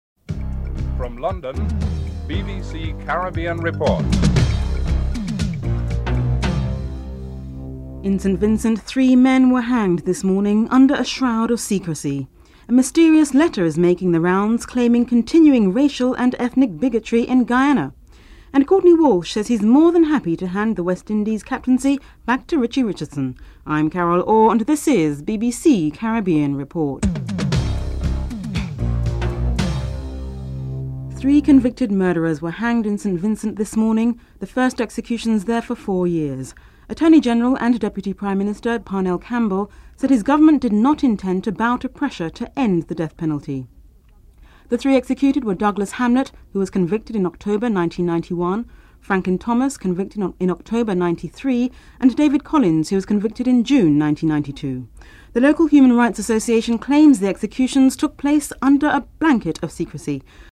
2. Report on three convicted murderers who were hanged in St. Vincent under a shroud of secrecy (00:30-04:33)